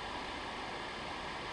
tv_noise.wav